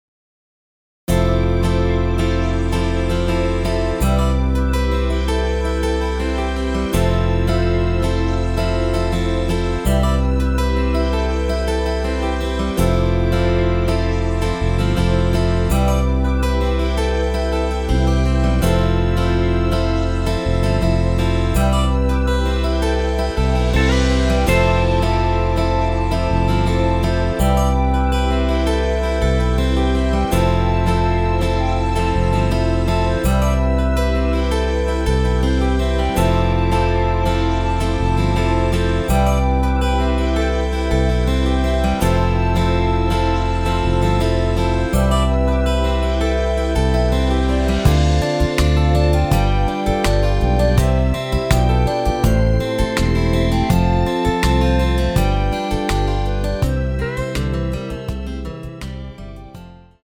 엔딩이 페이드 아웃이라 라이브 하시기 좋게 엔딩을 만들어 놓았습니다.